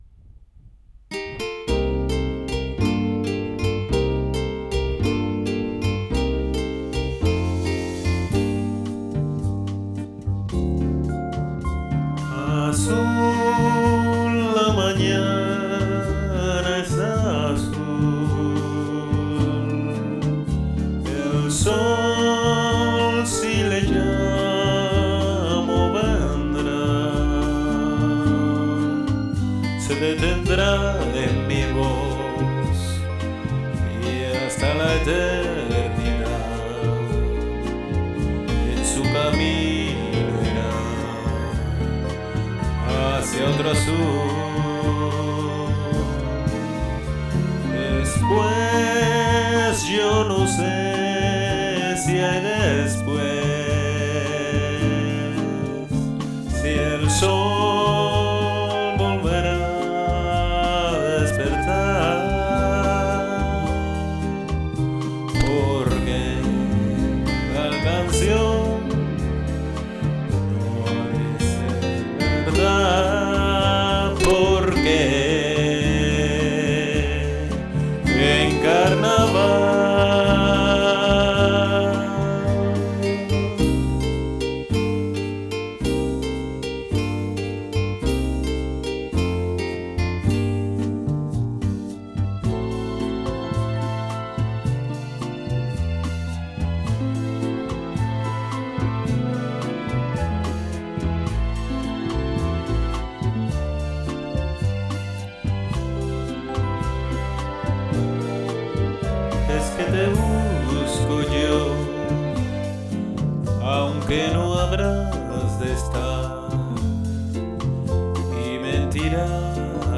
Me gusta cantar, pero sólo como aficionado.